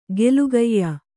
♪ gelugayya